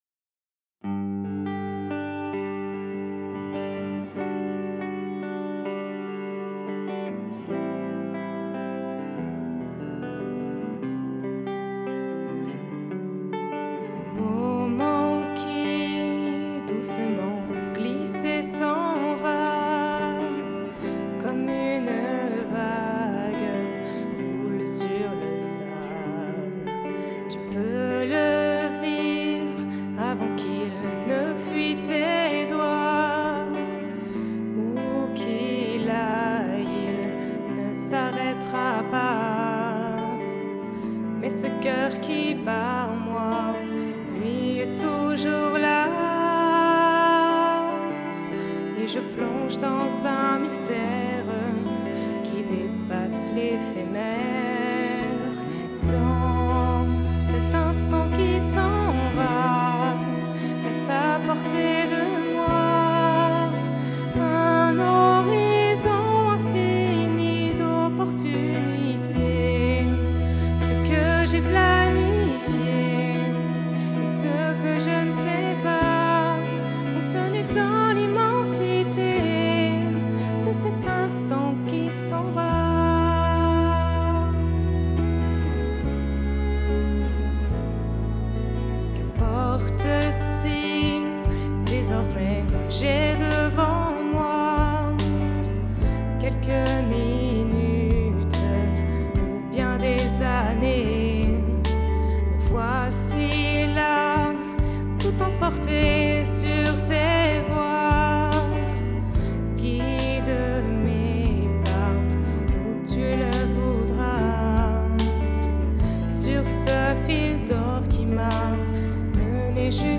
Enregistré lors du week-end unison : ./Dans l'instant qui s'en va.mp3